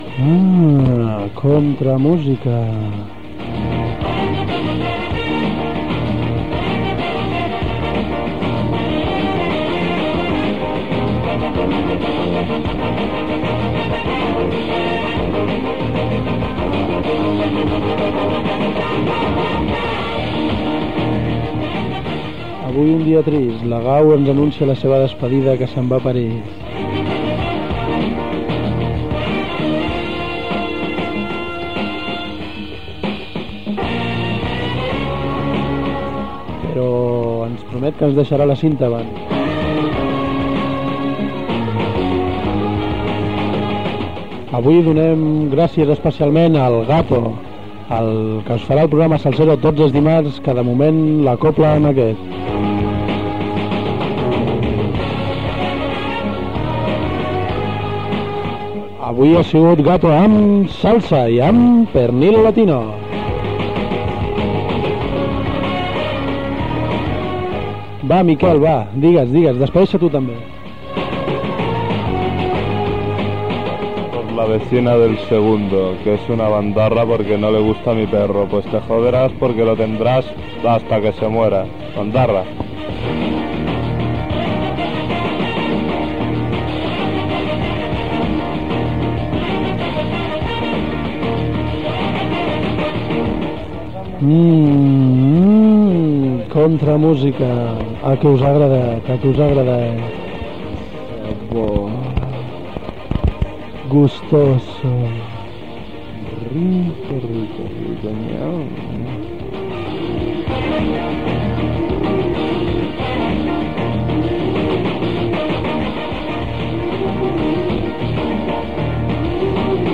Comiat del programa Gènere radiofònic Musical
Banda FM